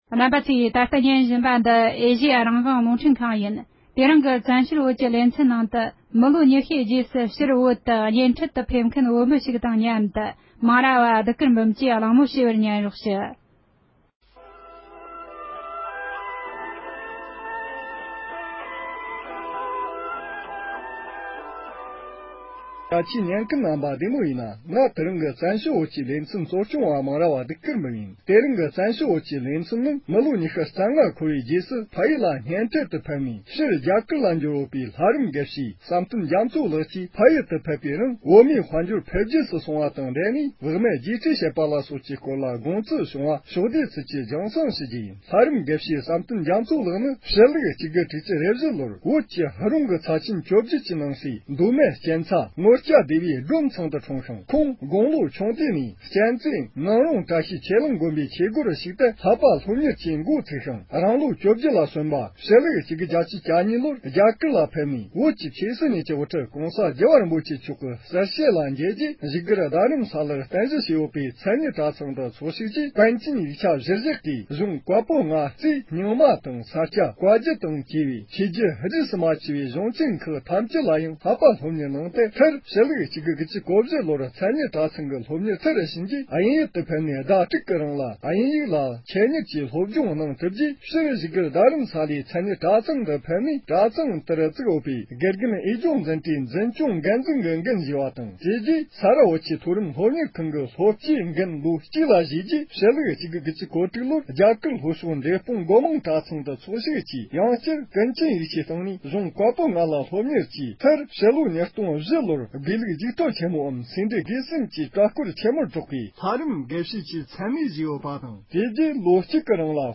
མི་ལོ་ཉི་ཤུའི་རྗེས་ཕྱིར་བོད་ལ་གཉེན་འཕྲན་དུ་ཕེབས་མཁན་གྱི་བོད་མི་དང་ལྷན་དུ་བགྲོ་གླེང་གནང་བ།